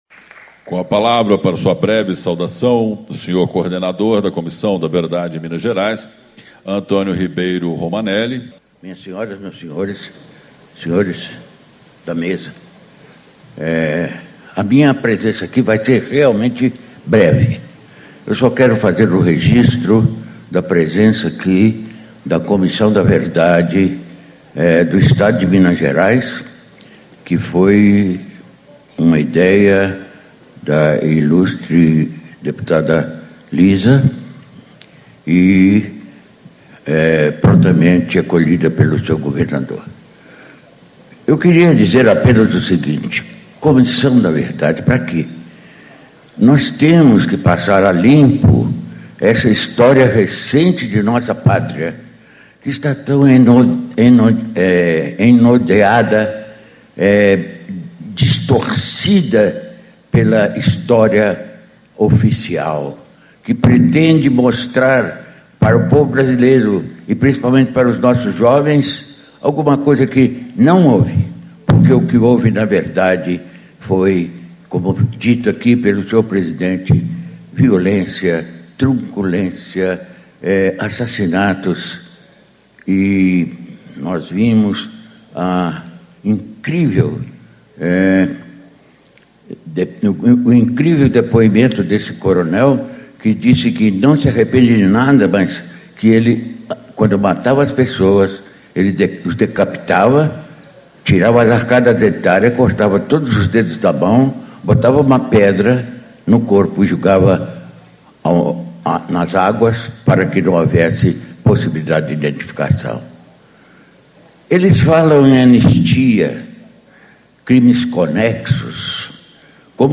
Abertura - Antônio Ribeiro Romaneli - Coordenador da Comissão da Verdade de Minas Gerais
Discursos e Palestras